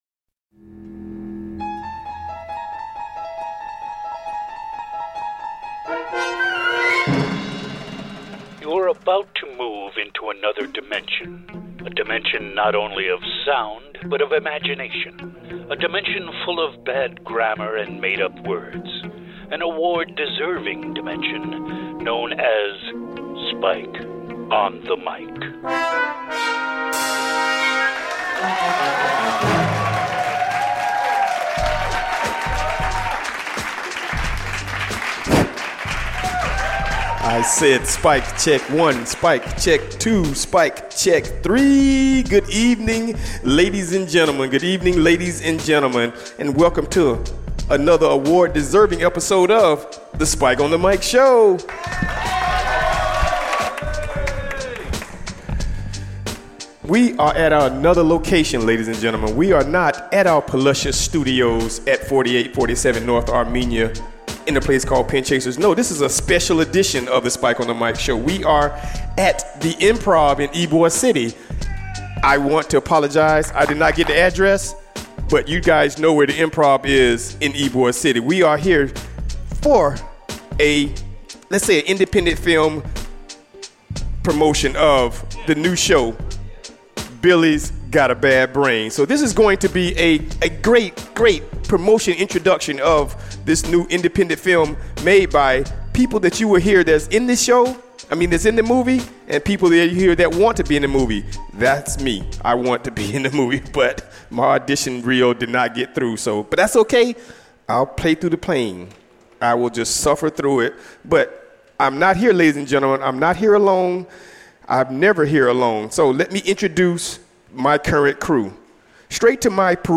Live from Improv in Tampa 7-12-17